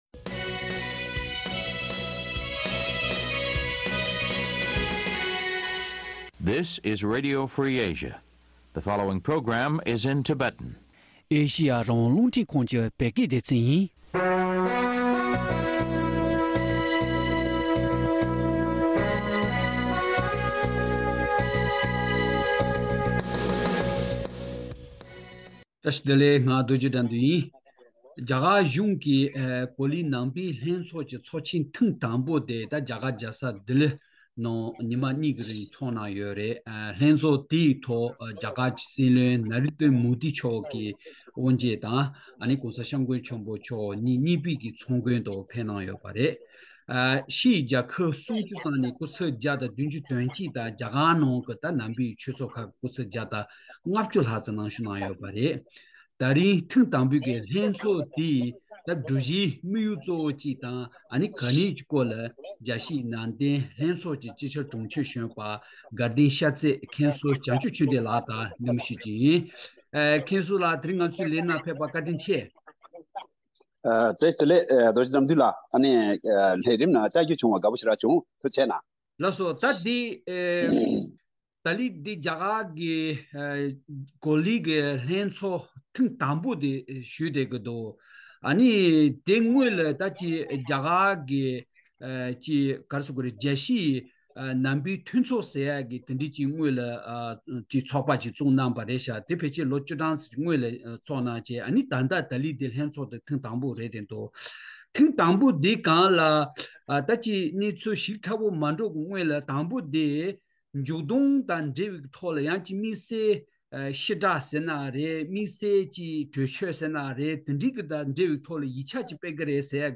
ལྷན་བགྲོ་གླེང་བྱས་པ་ཉན་རོགས་ཞུ།